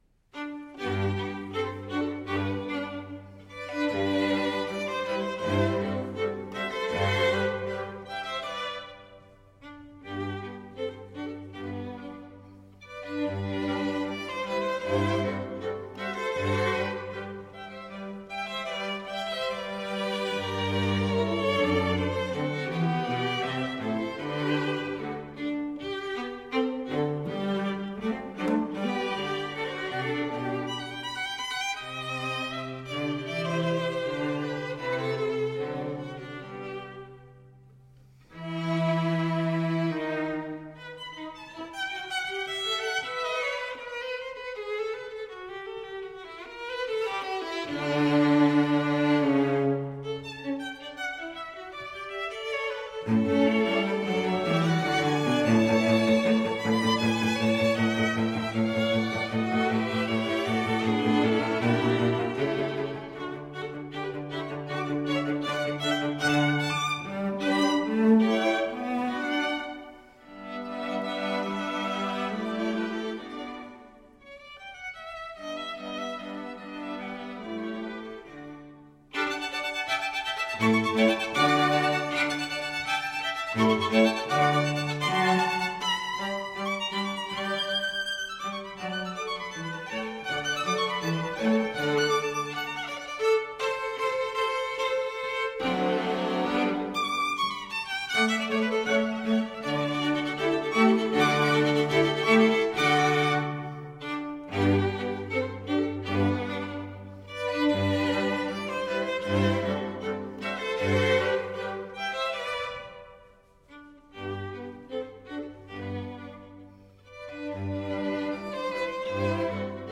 String Quartet
Style: Classical
Audio: Boston - Isabella Stewart Gardner Museum
Audio: Musicians from Marlboro (string quartet)